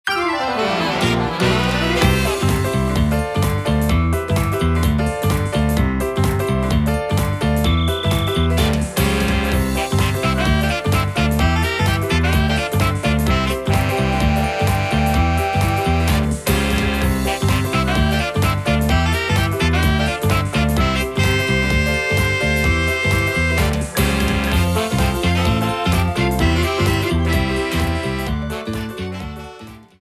The battle music
Edited Shortened, applied fade-out and converted to oga